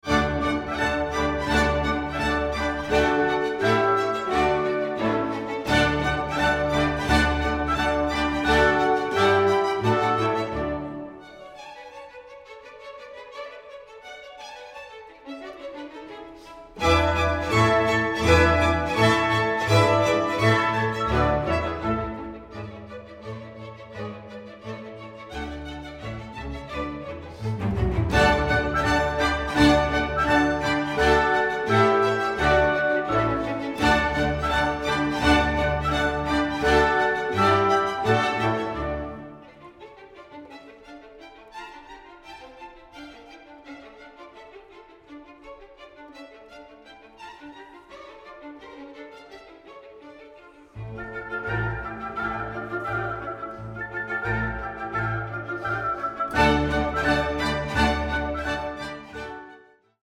Presto assai